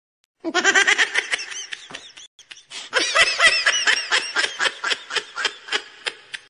Reactions
Goofy Ahh Laughing Baby